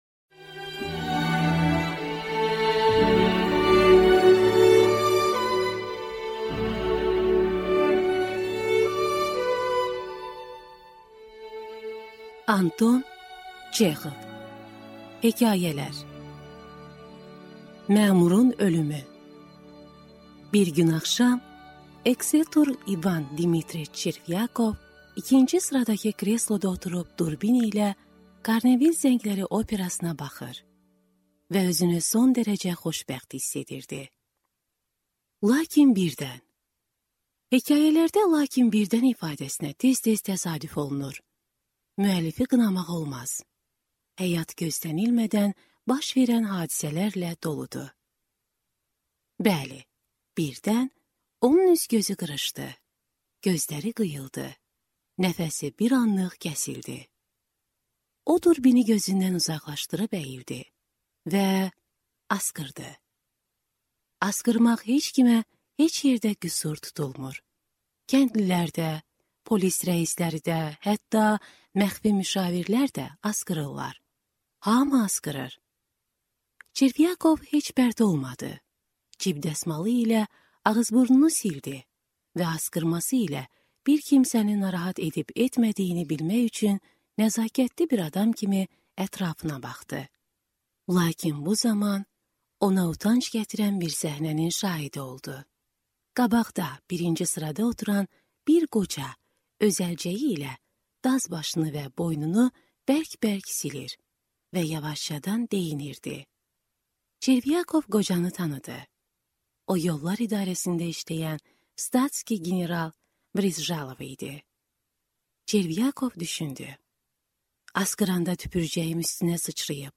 Аудиокнига Hekayələr